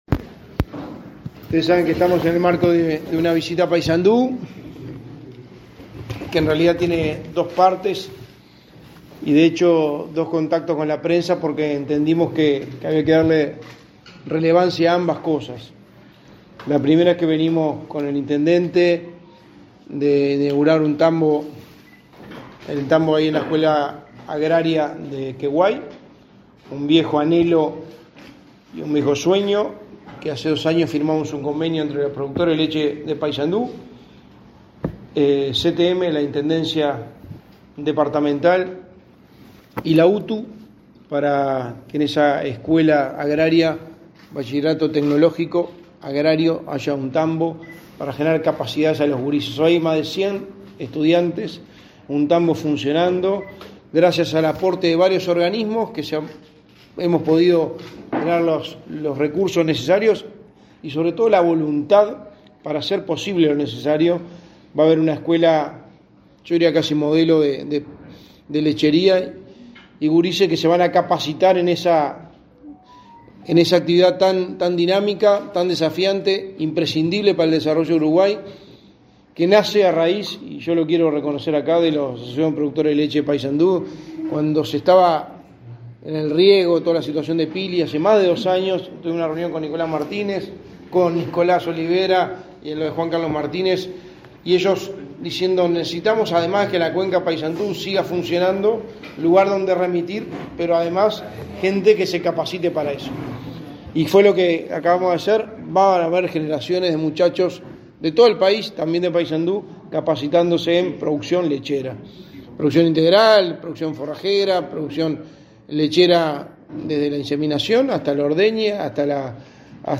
Conferencia de prensa en el Hospital de Paysandú por la inauguración centro de cuidados paliativos
Con la presencia del secretario de la Presidencia, Álvaro Delgado, y del presidente de la Administración de los Servicios de Salud del Estado (ASSE),